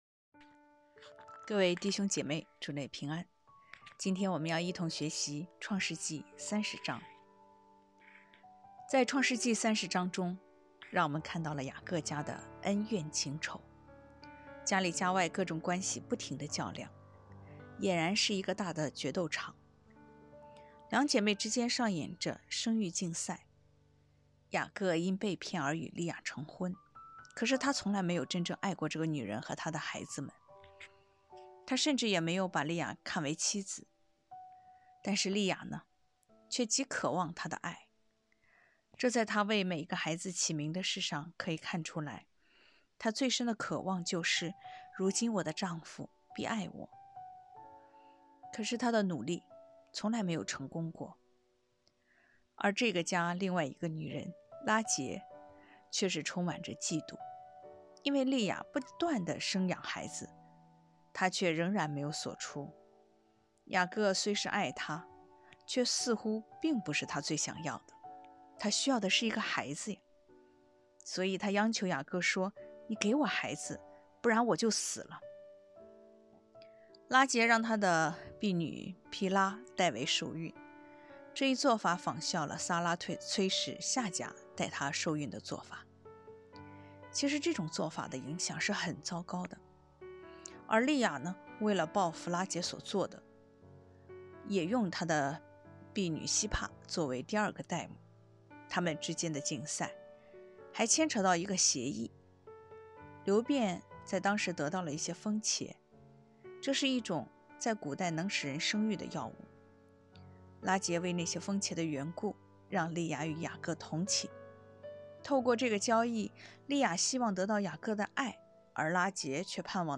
课程音频：